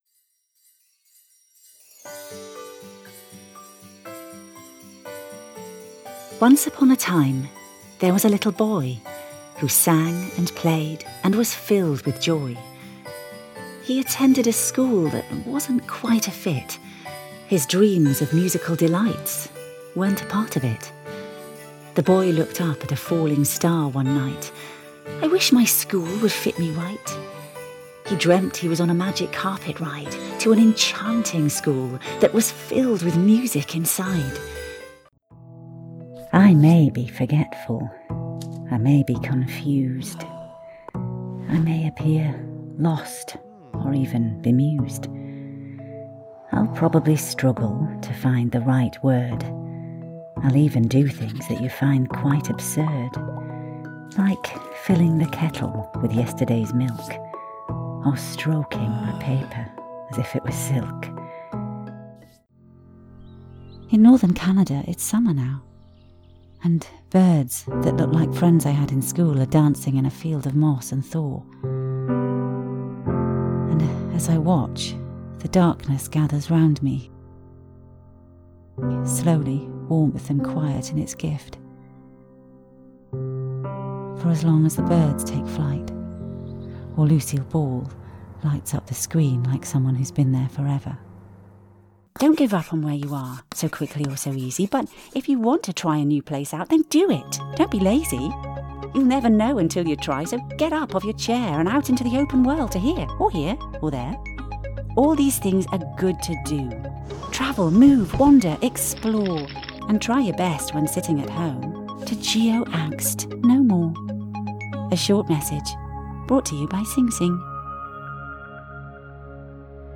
British English Female Voice Over Artist
Female
Assured, Authoritative, Confident, Corporate, Engaging, Friendly, Gravitas, Reassuring, Soft, Warm, Witty
Clients have told me that my voice hits a sweet spot - authoritative, knowledgeable and intelligent, while still being warm and approachable.
Explainer2018.mp3
Microphone: Audio Technica AT2030